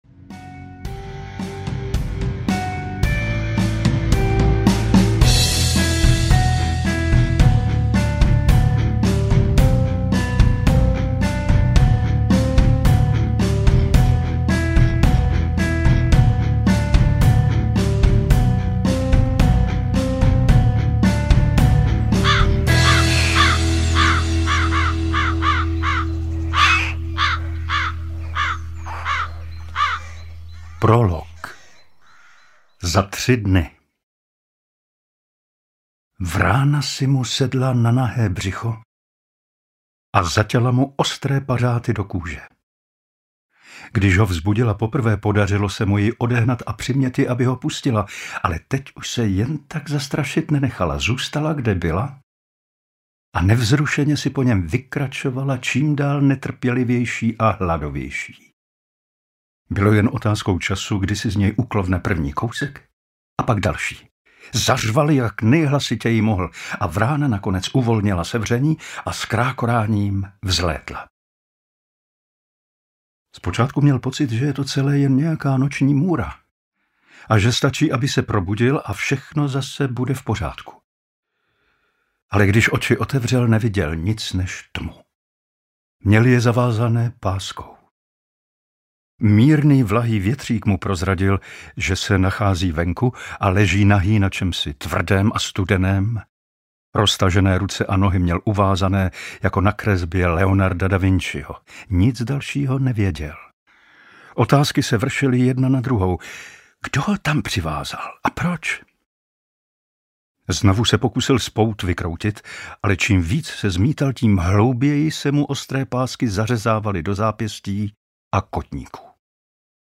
Oběť bez tváře - 2. vydání audiokniha
Ukázka z knihy
• InterpretPavel Soukup
obet-bez-tvare-2-vydani-audiokniha